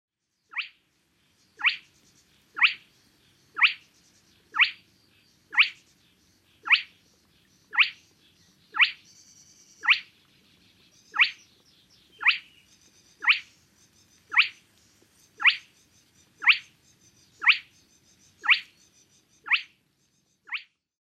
Rytmit ovat helposti tunnistettavia ja yksinkertaisia.
Luhtahuitilla puolestaan on tasaisen iskeviä piiskansivalluksia, jotka voivat jatkua tuntikausia lyhyin tauoin.